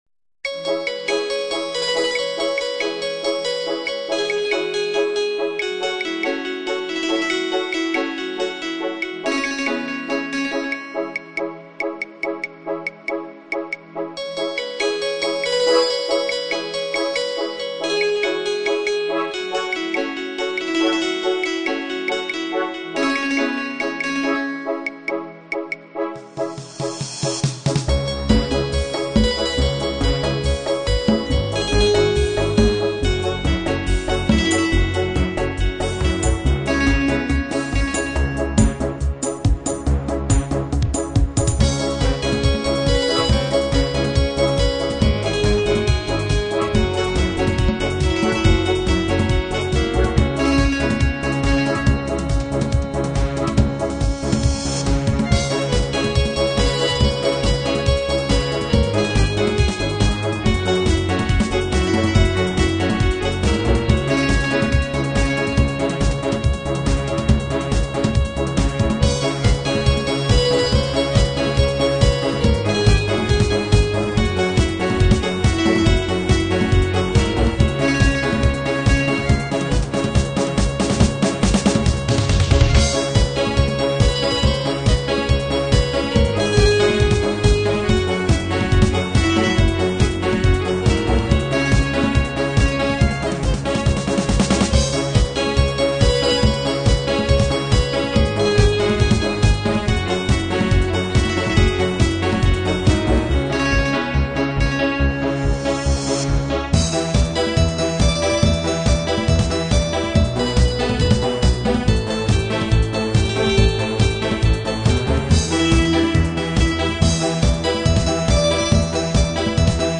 Hely szűke miatt csak 32 Khz-es a minőség, de így is szépen szól.
(A lejátszáshoz a kiírás szerint Sound Blaster Live hangkártyát használtunk. Egyes zenéknél így is előfordulhat, hogy más, illetve másként szólal meg, mint a szerző remélte.)